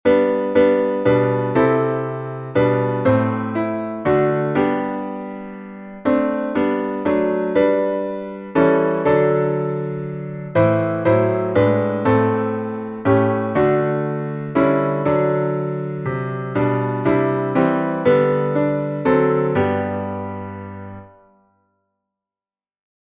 salmo_43B_playback.mp3